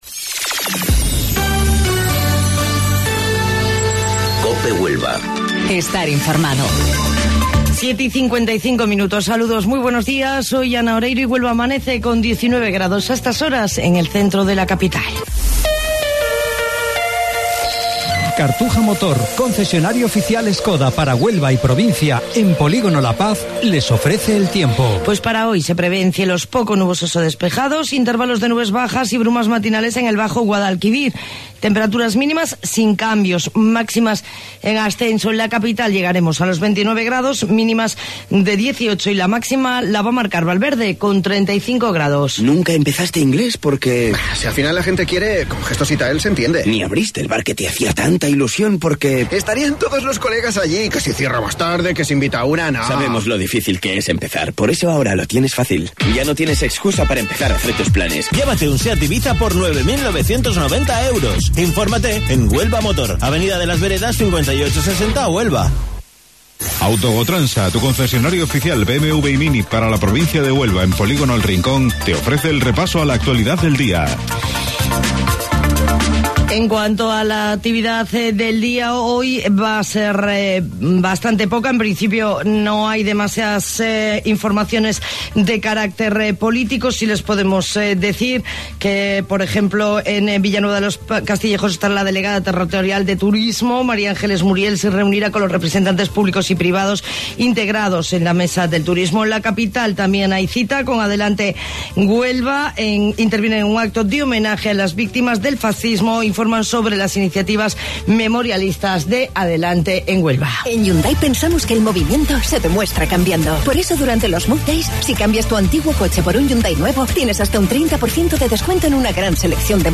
AUDIO: Informativo Local 07:55 del 18 de Julio